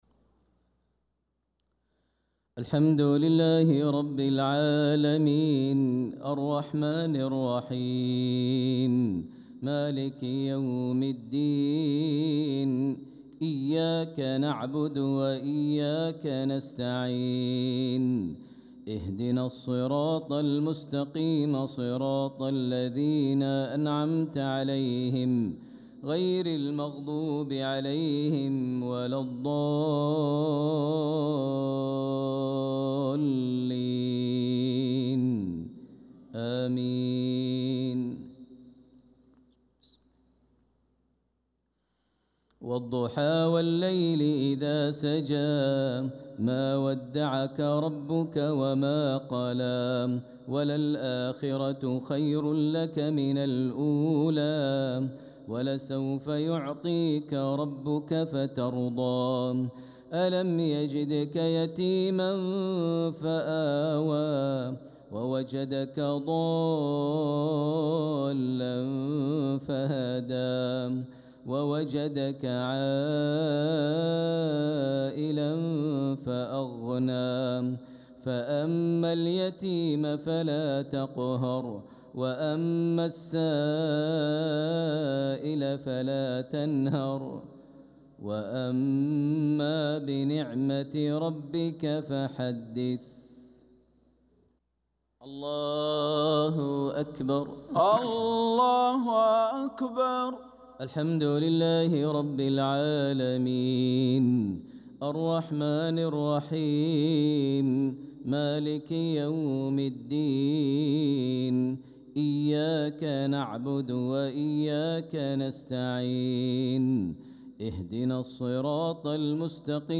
صلاة العشاء للقارئ ماهر المعيقلي 26 صفر 1446 هـ
تِلَاوَات الْحَرَمَيْن .